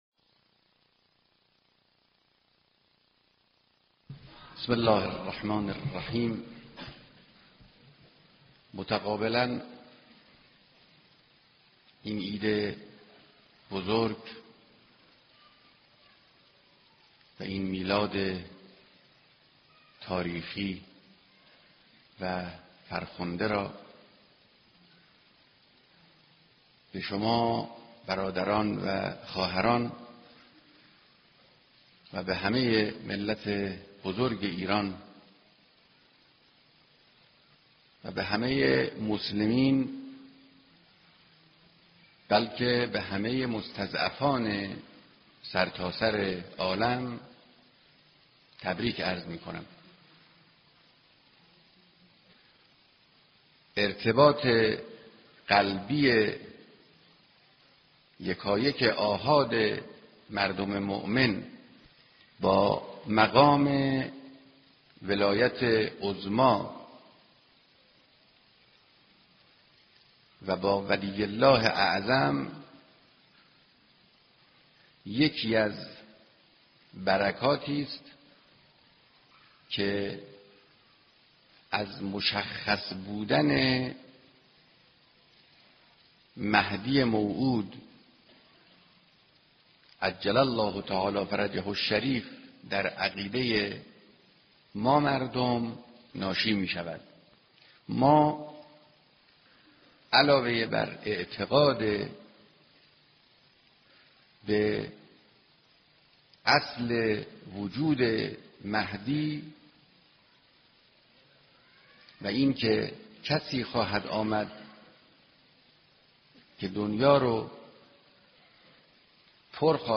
صوت کامل بیانات
سخنرانی